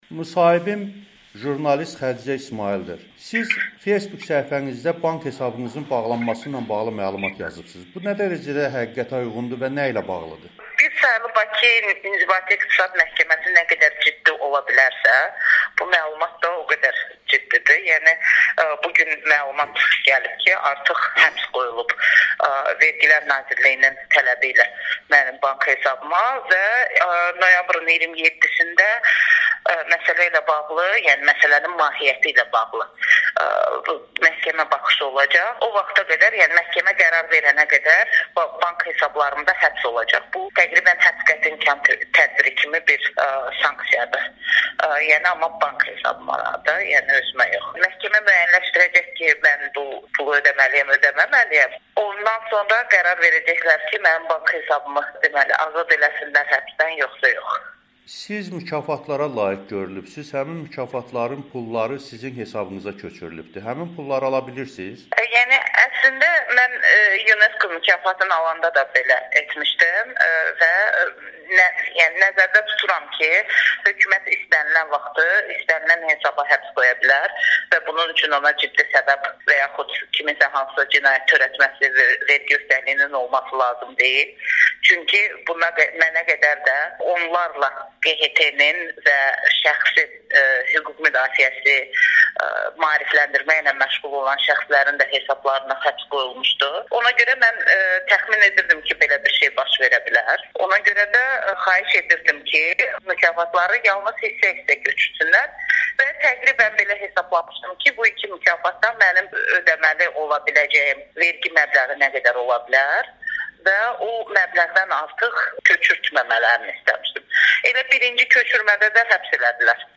Araşdırmaçı jurnalist Xədicə İsmayılın Amerikanın Səsinə müsahibəsi